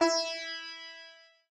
sitar_e.ogg